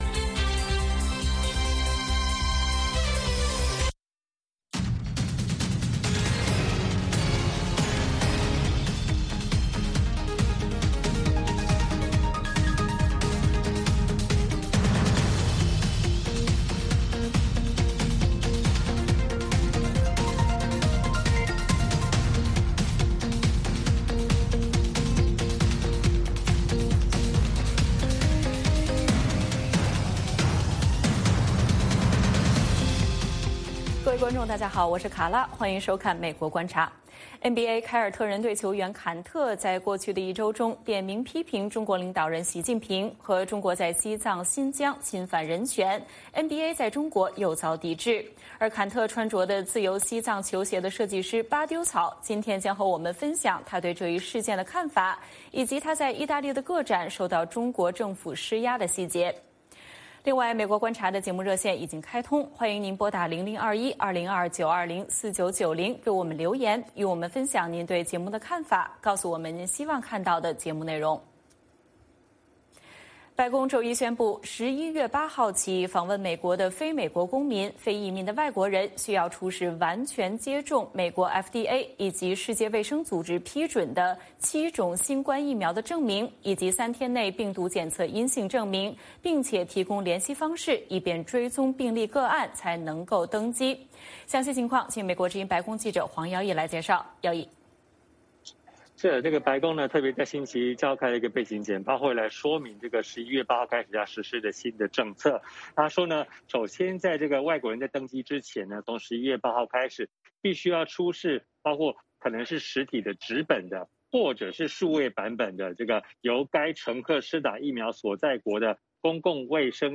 北京时间早上6点广播节目，电视、广播同步播出VOA卫视美国观察。 “VOA卫视 美国观察”掌握美国最重要的消息，深入解读美国选举，政治，经济，外交，人文，美中关系等全方位话题。节目邀请重量级嘉宾参与讨论。